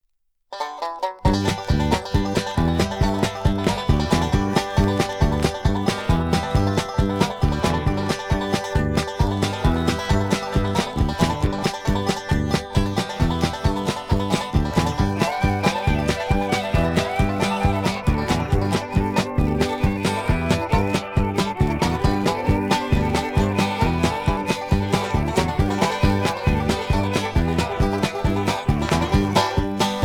(two instrumentals)